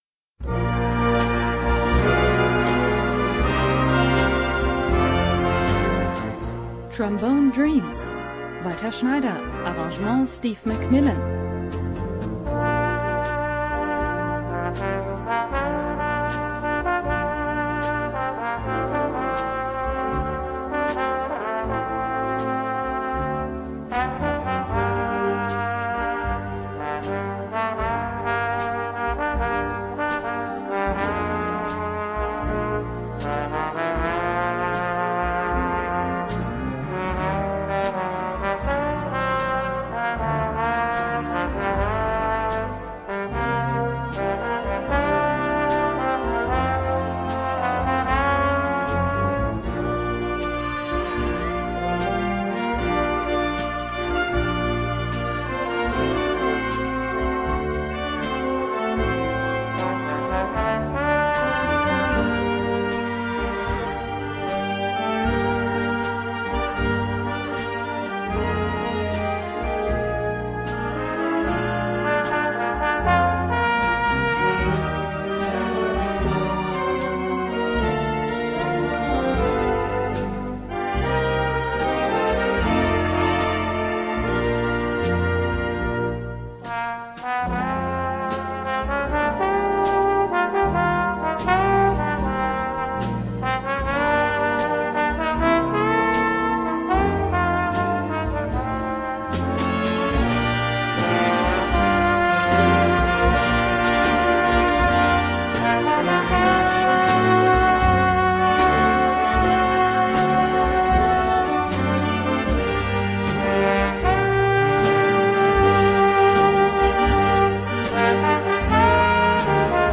Gattung: Blues für Soloposaune und Blasorchester
Besetzung: Blasorchester